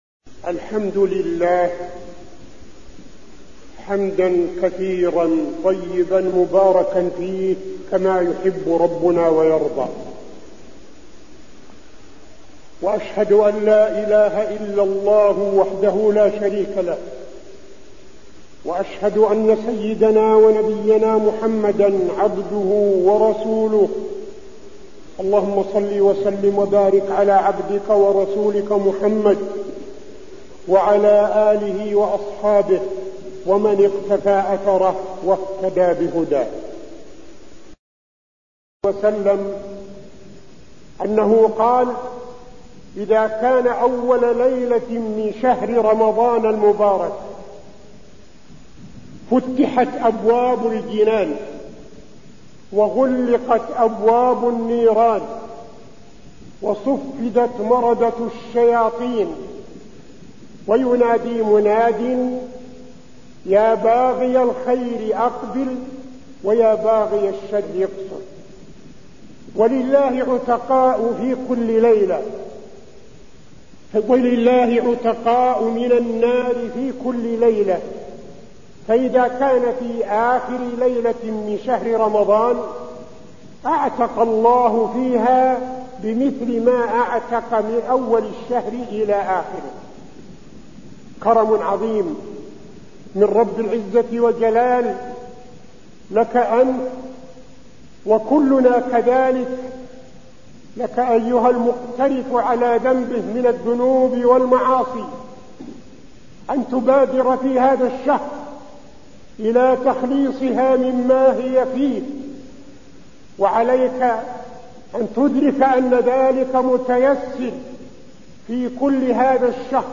تاريخ النشر ٨ رمضان ١٤٠٦ هـ المكان: المسجد النبوي الشيخ: فضيلة الشيخ عبدالعزيز بن صالح فضيلة الشيخ عبدالعزيز بن صالح الحث على المبادرة للتوبة